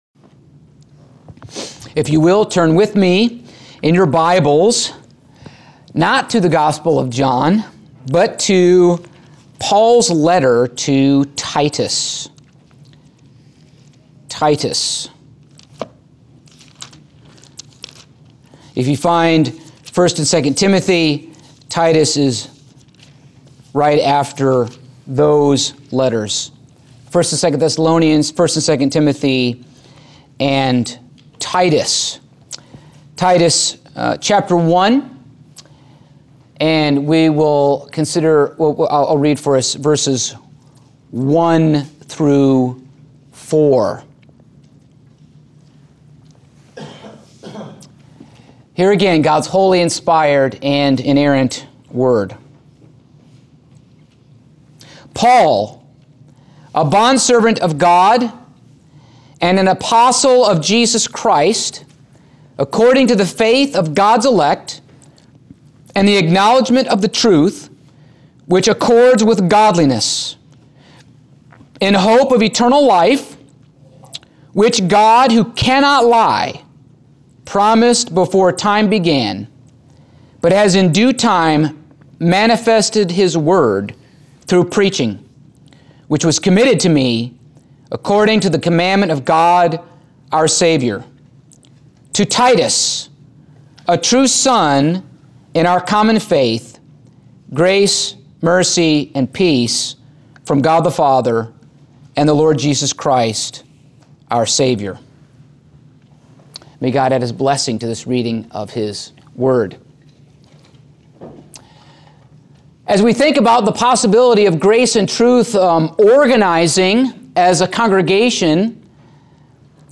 Passage: Titus 1:1-4 Service Type: Sunday Morning